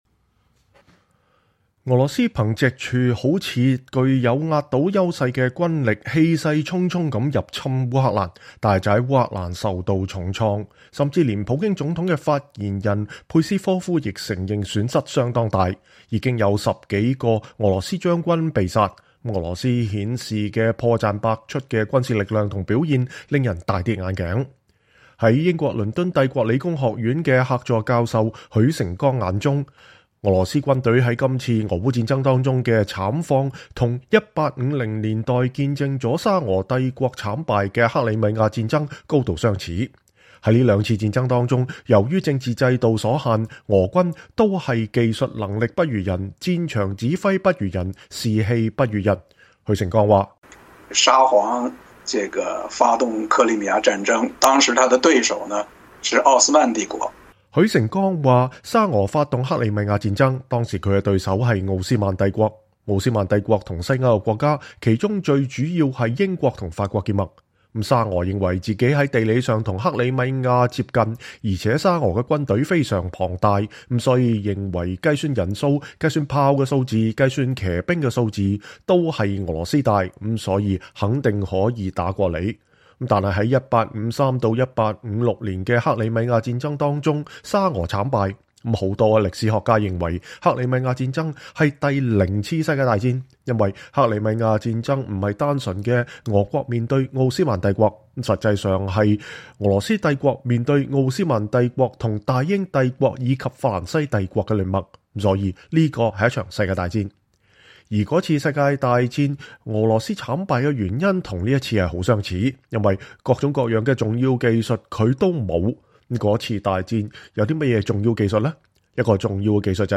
專訪許成鋼（2）：俄軍在俄烏戰爭中敗績的歷史和制度原因